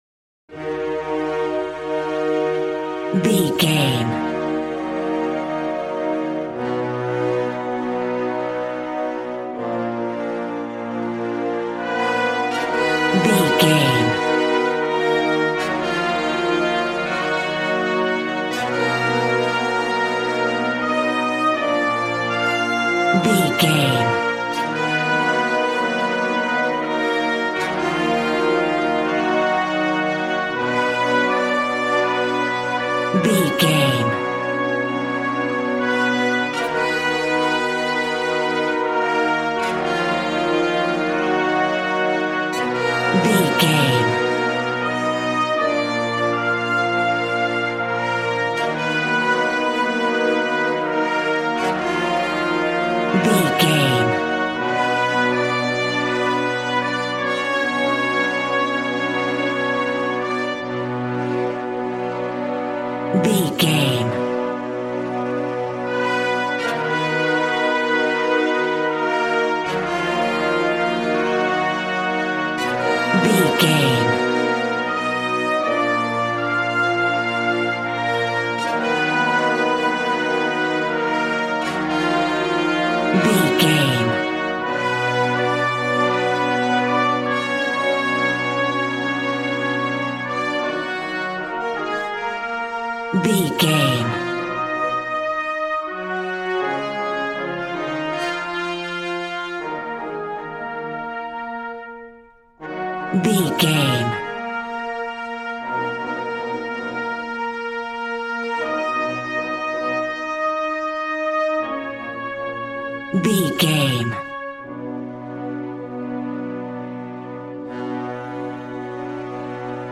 Regal and romantic, a classy piece of classical music.
Aeolian/Minor
E♭
regal
cello
double bass